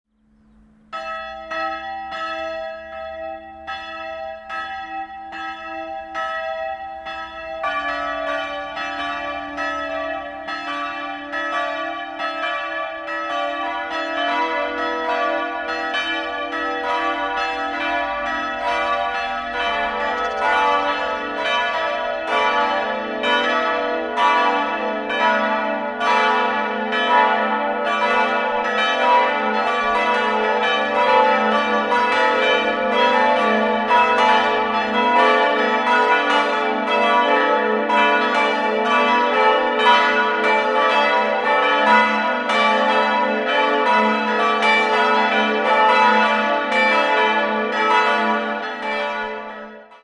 Das Kirchenschiff war früher ein Kino und wurde 1961/62 zur heutigen Kirche umgebaut. 4-stimmiges Gloria-TeDeum-Geläute: a'-h'-d''-e'' Die Glocken wiegen 496, 360, 257 und 173 kg und wurden 1964 von Friedrich Wilhelm Schilling gegossen.